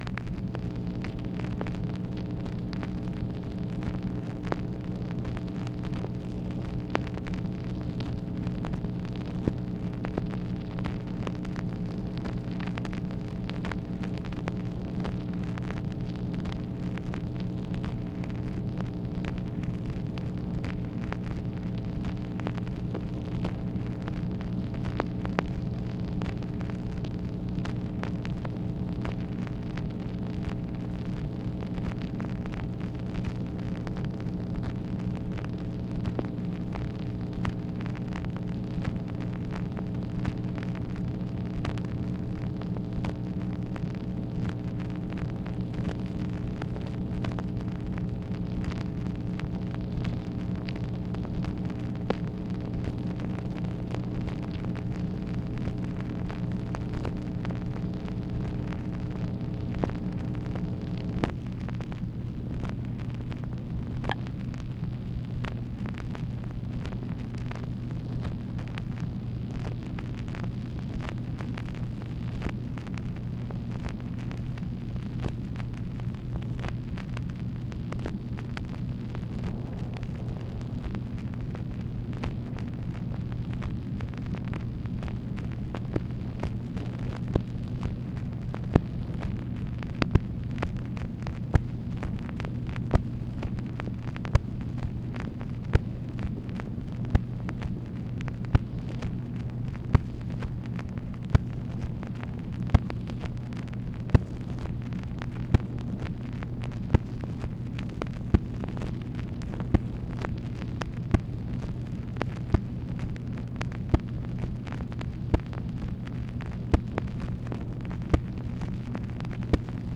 MACHINE NOISE, August 5, 1964
Secret White House Tapes | Lyndon B. Johnson Presidency